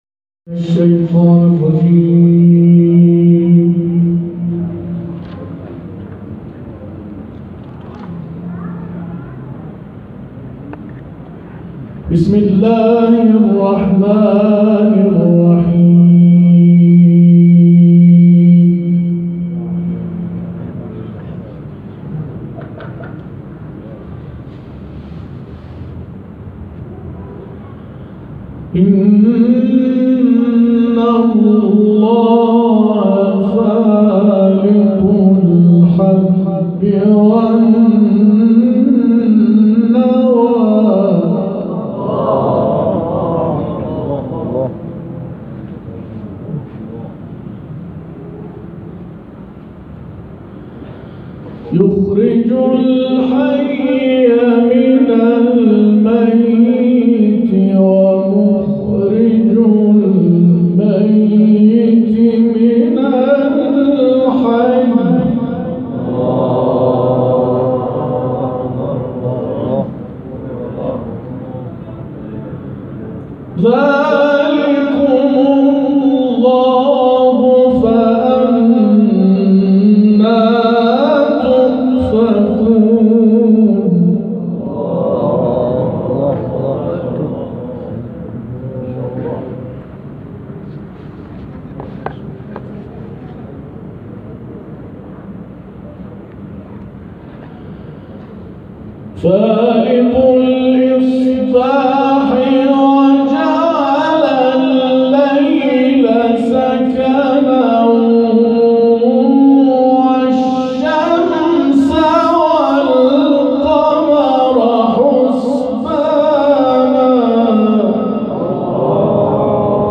جدیدترین تلاوت احمد ابوالقاسمی + دانلود
گروه فعالیت‌های قرآنی: محفل انس با قرآن‌ کریم، شب گذشته با تلاوت احمد ابوالقاسمی در مسجد حضرت ولیعصر(عج) برپا شد.